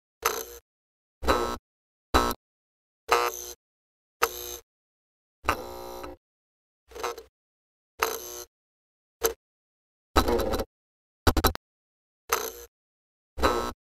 Погрузитесь в атмосферу ночного города с подборкой звуков неона: мерцание вывесок, тихий гул ламп, электронные переливы.
Шепот неона: лампа слегка барахлит